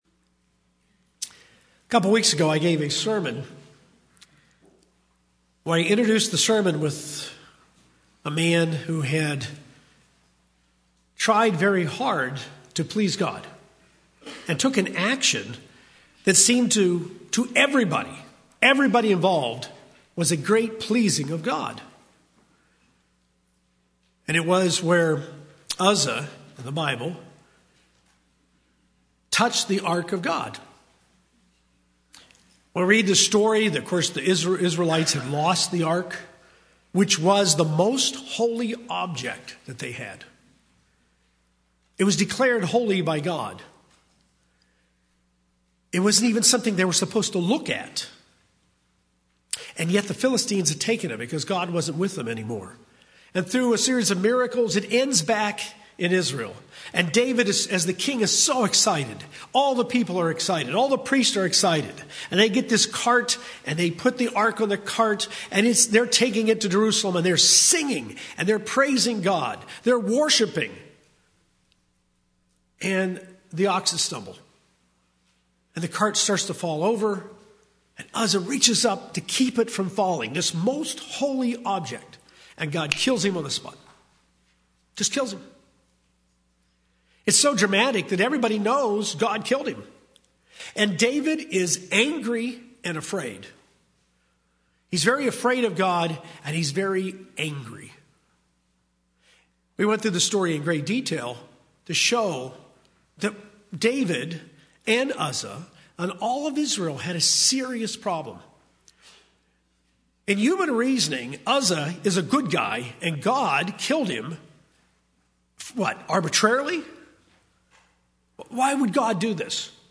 This sermon is the second installment in a series on sanctification.